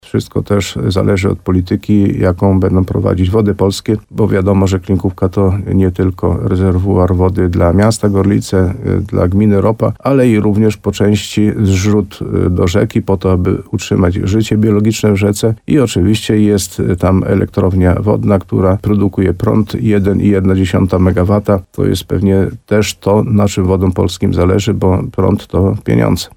Jak mówił w programie Słowo za Słowo w radiu RDN Nowy Sącz wójt gminy Ropa Karol Górski, jezioro może zapełnić się w tym sezonie nawet do 100%, ale to nie tylko kwestia pogody